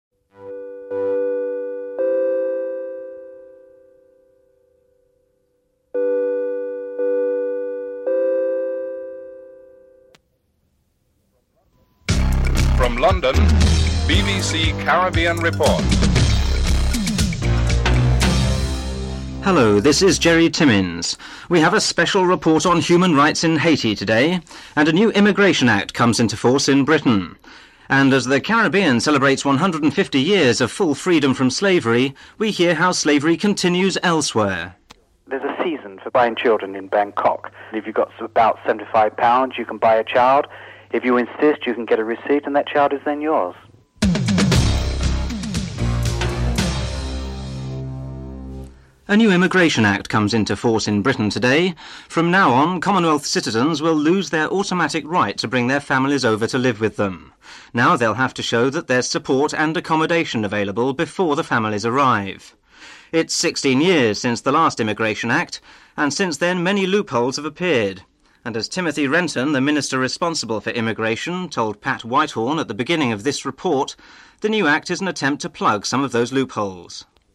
2. New immigration act comes into force in Britain, removing the automatic right of Commonwealth citizens to bring their families to live with them.